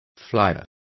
Complete with pronunciation of the translation of fliers.